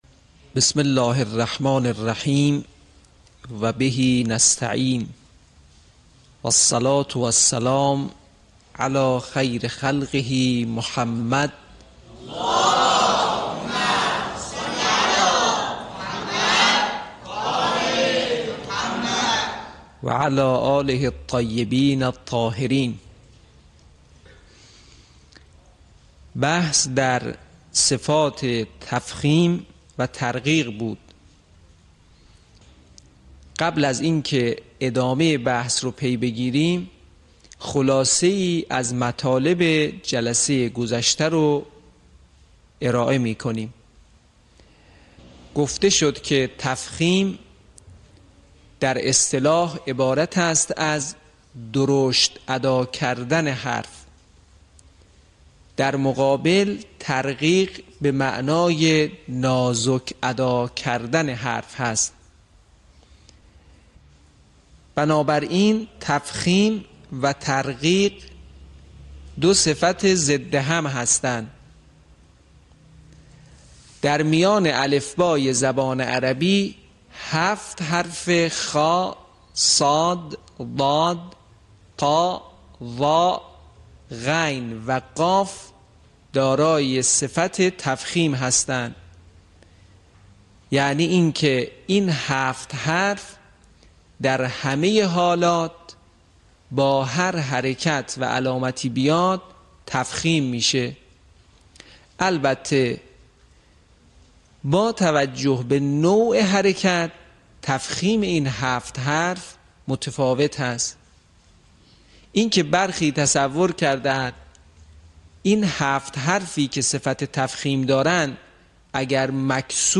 صوت | آموزش احکام تغلیظ
به همین منظور مجموعه آموزشی شنیداری (صوتی) قرآنی را گردآوری و برای علاقه‌مندان بازنشر می‌کند.